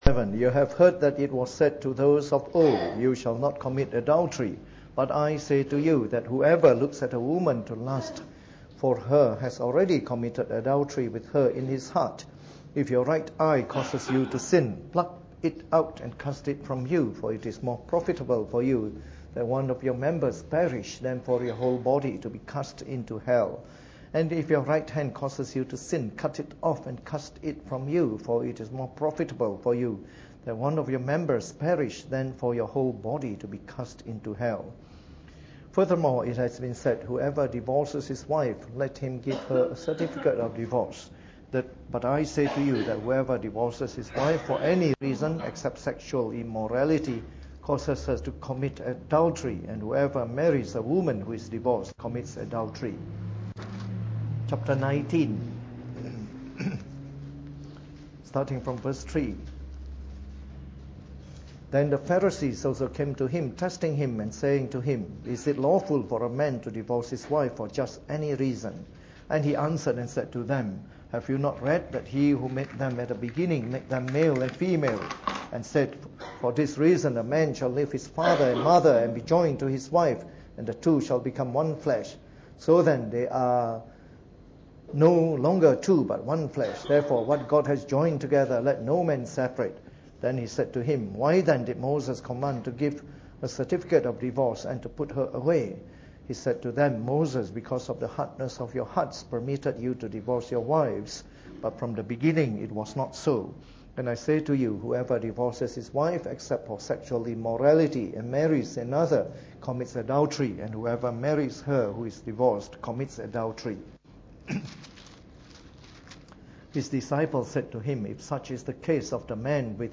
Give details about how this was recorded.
Preached on the 24th of June 2015 during the Bible Study, from our new series on “Christian Marriage.”